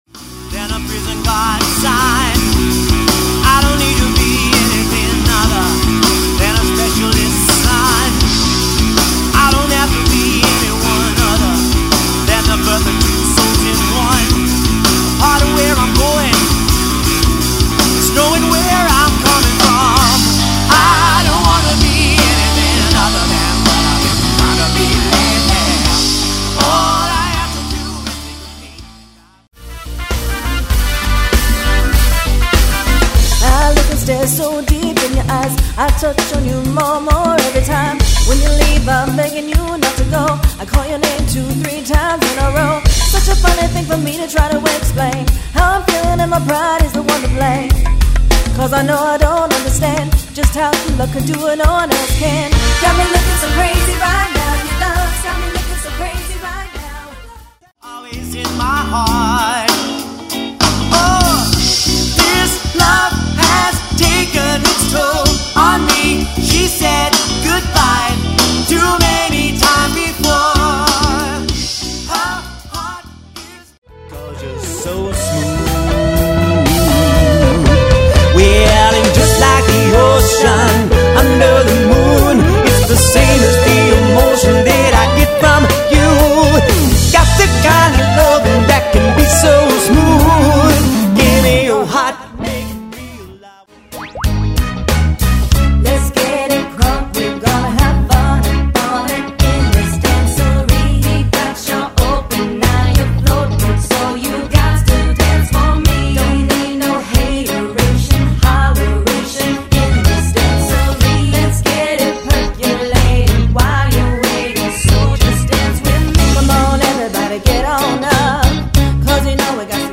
A dynamic and high-impact band
a fantastic party band